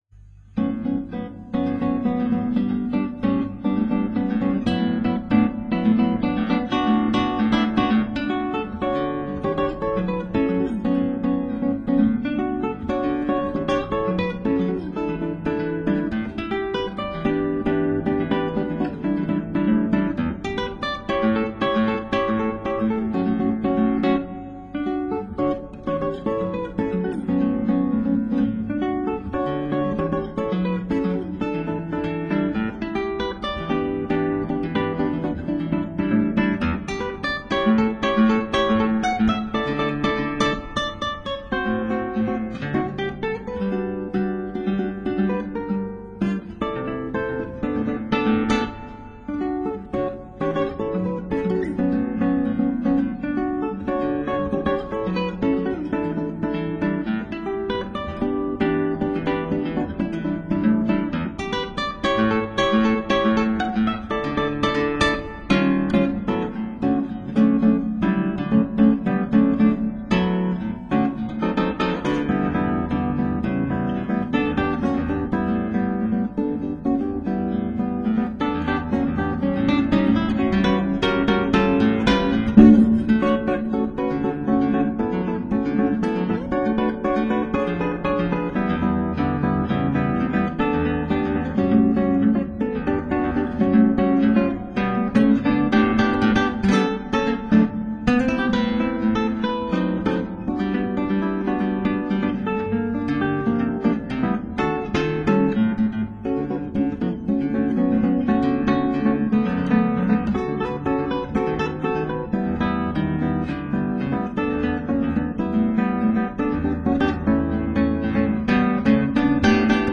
クラシックギター　ストリーミング　コンサートサイト
ボサノバ独特ののりがなかなか出せなくて苦労しました。後半しくじっているところもありますが気に入っているので乗せました。